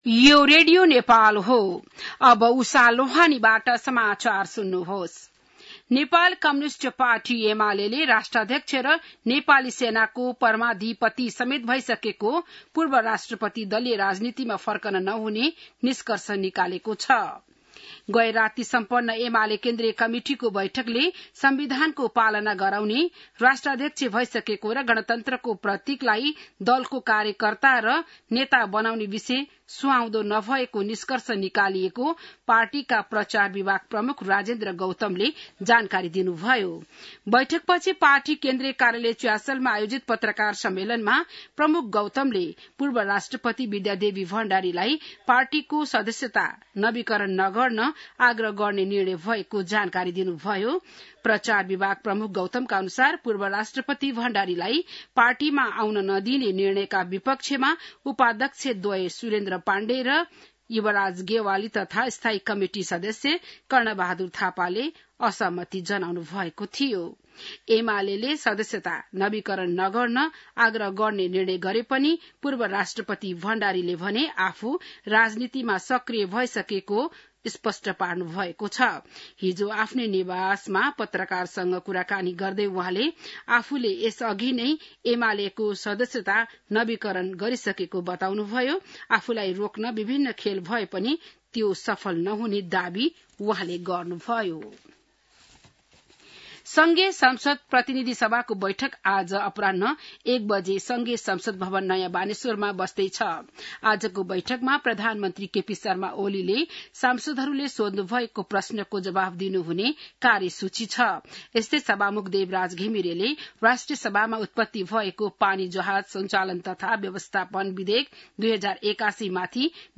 बिहान १० बजेको नेपाली समाचार : ७ साउन , २०८२